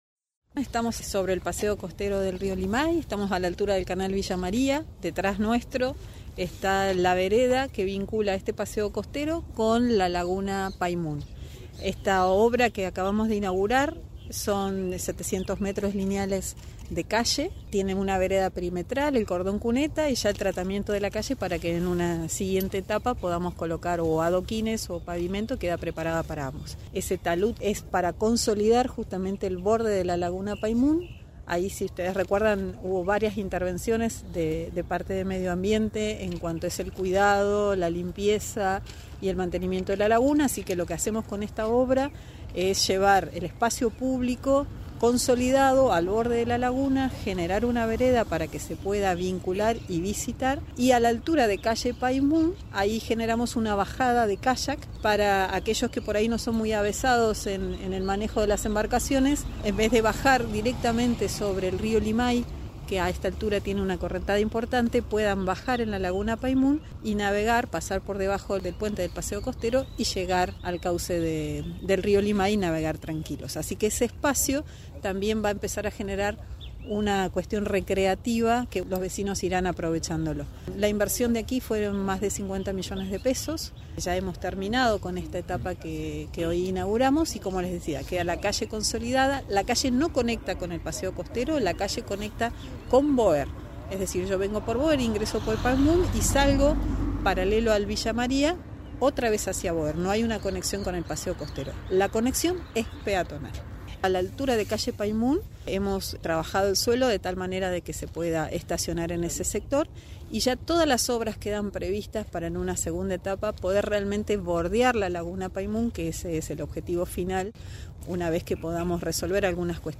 Mariel Bruno, subsecretaria de Infraestructura.